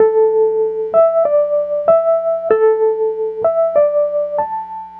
Track 14 - Wurlitzer 01.wav